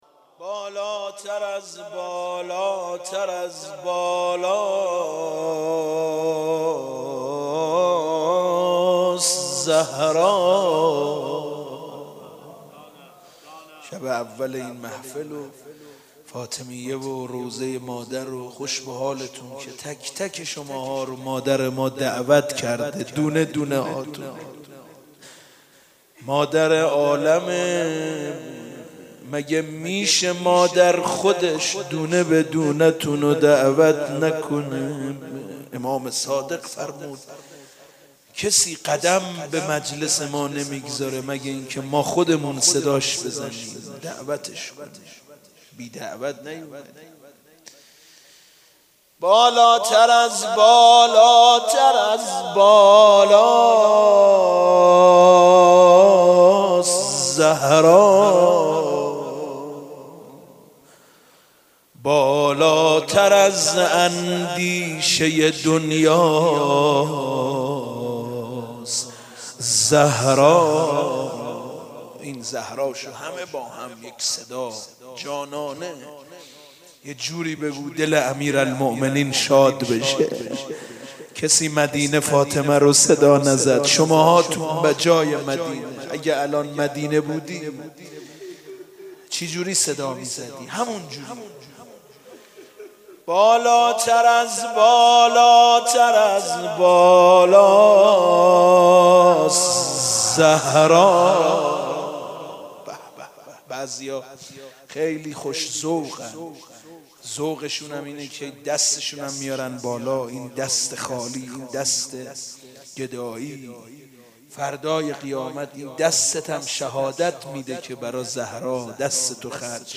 مدح - بالاتر از بالاست زهرا